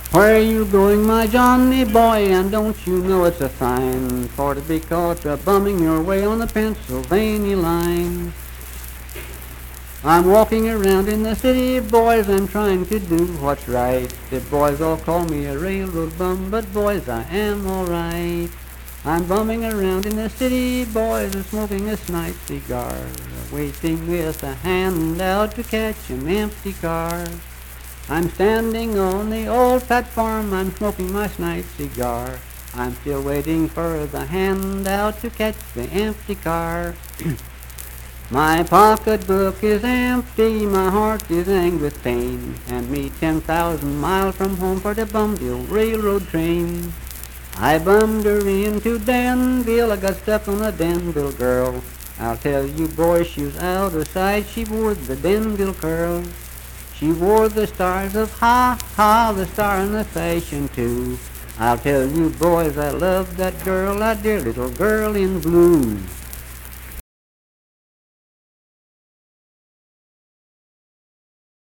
Unaccompanied vocal music performance
Voice (sung)